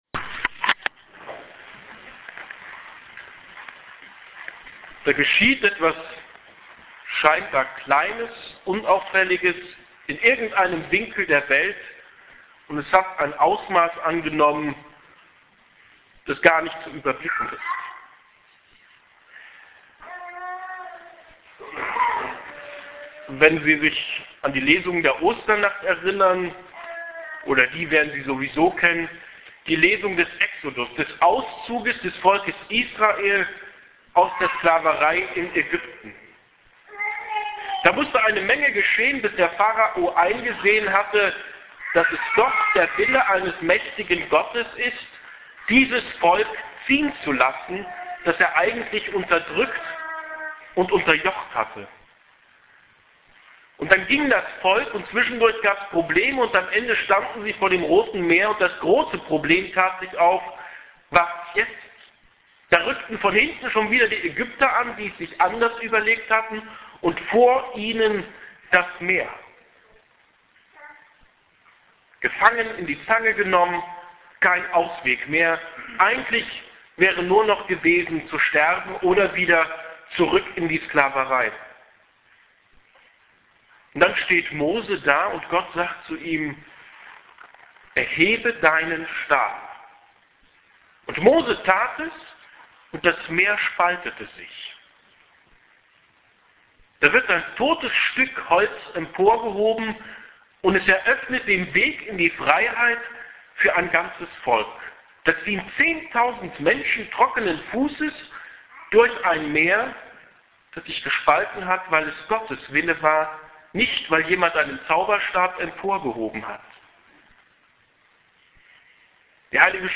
Osternacht 2012_hier klickt die Predigt